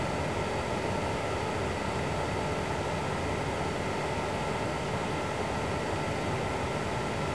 ファンノイズ比較